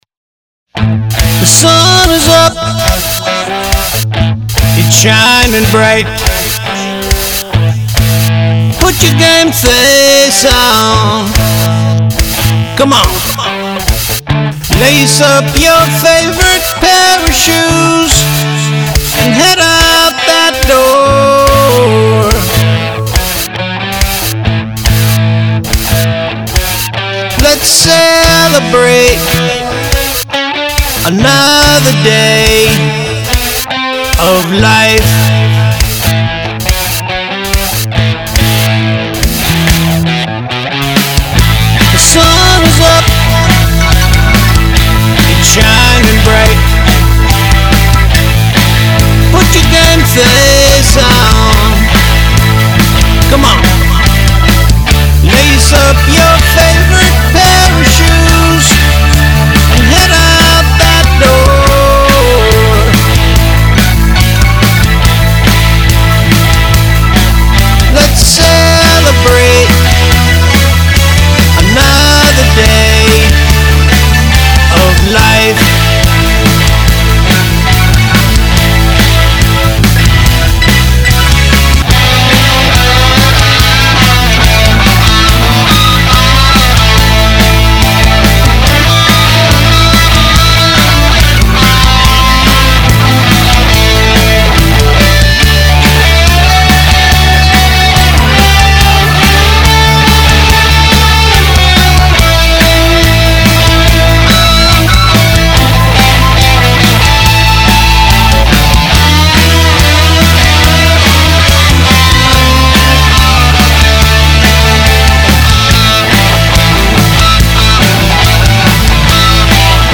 IRI-DESCENT Pop Tunes